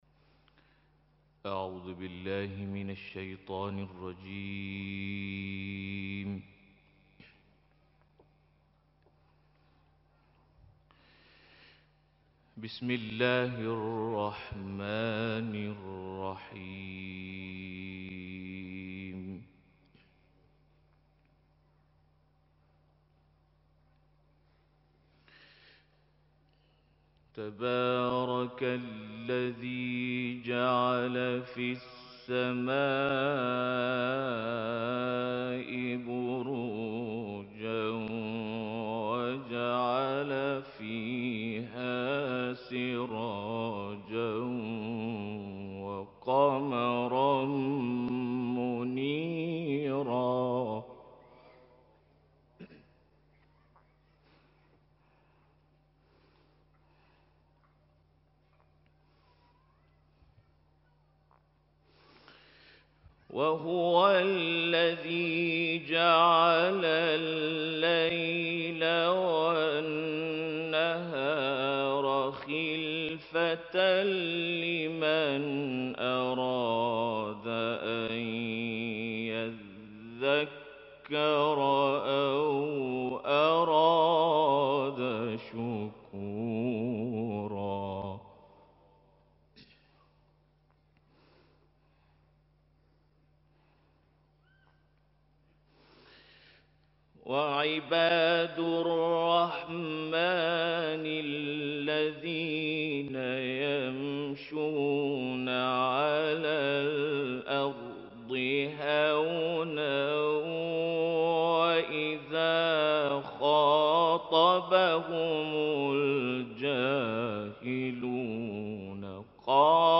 تلاوت منتخب مسابقات قرآن مالزی در اختتامیه